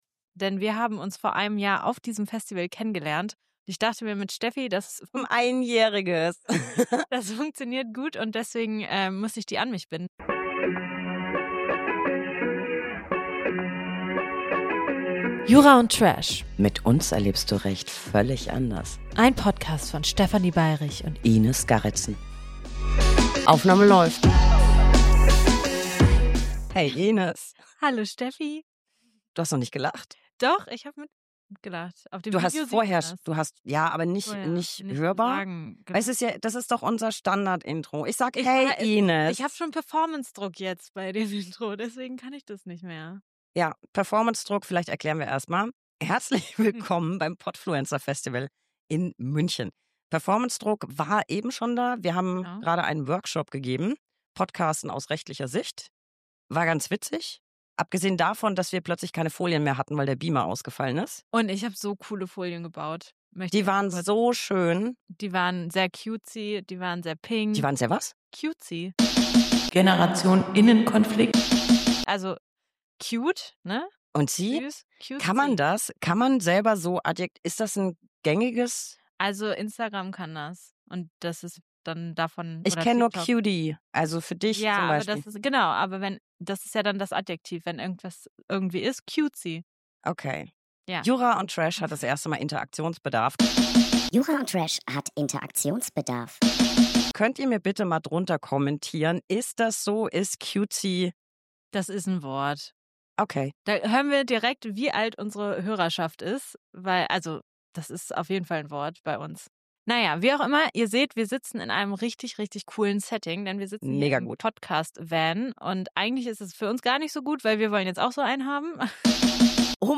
Live on Tape vom Podfluencer-Festival in München.